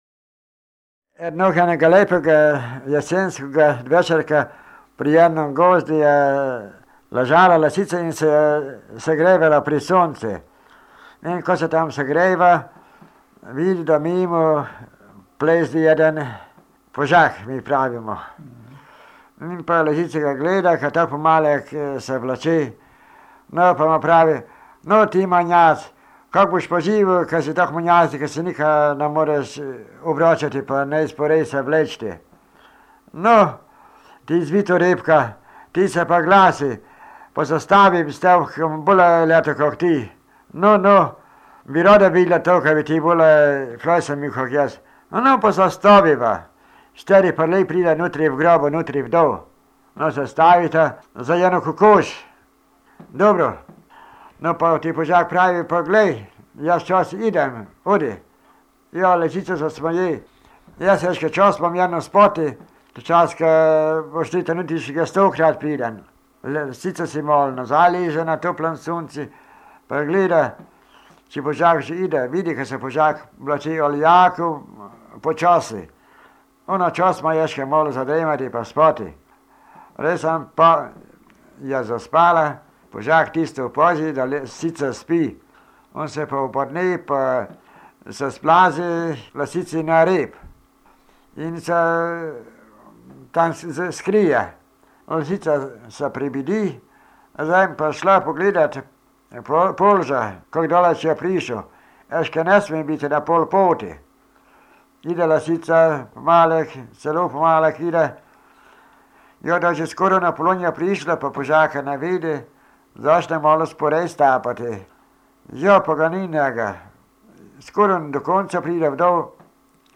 V knjigi so zbrane porabske pravljice in povedke, ki jih je leta 1970 posnel Milko Matičetov na magnetofonske trakove.
Dodana je zgoščenka s tonskimi posnetki trinajstih pravljic in povedk v obeh različicah porabskega narečja (števanovskem in gornjeseniškem).